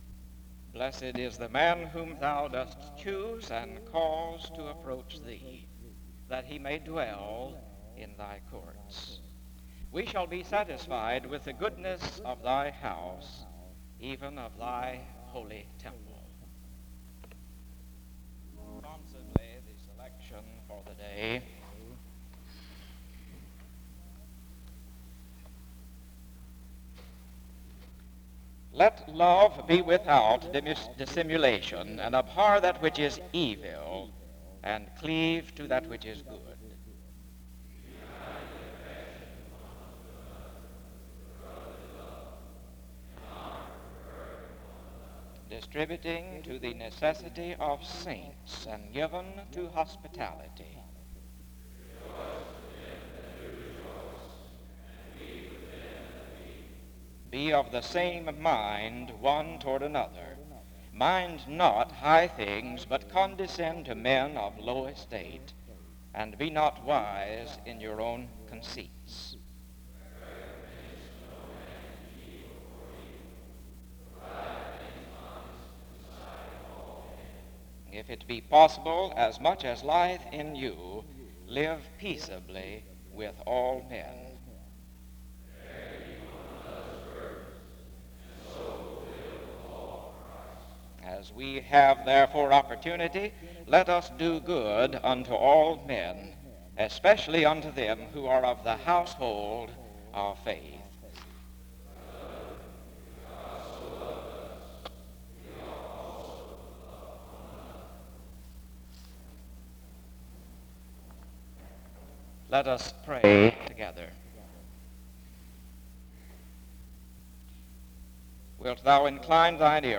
All Saints' Day sermons